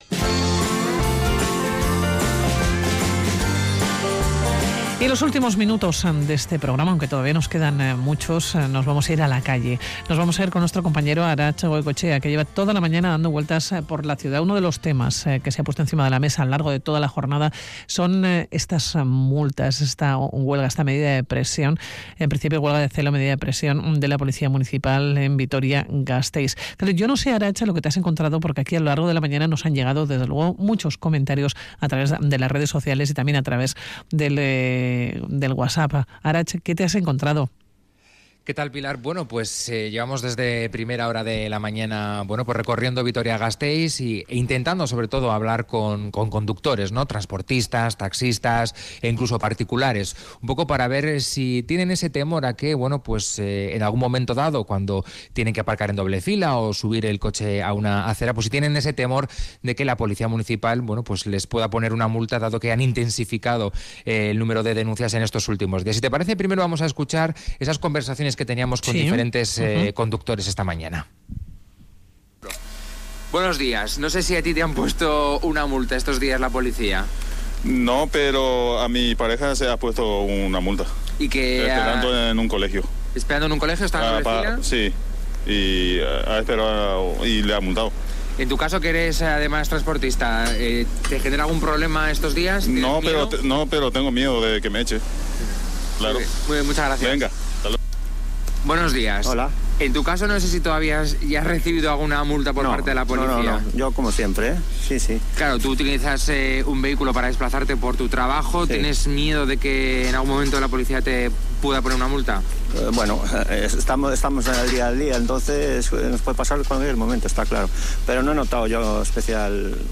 Audio: Ante el incremento de multas en los últimos días por parte de la Policía Local, hablamos con el Síndico para responder a las dudas sobre esta "huelga de celo".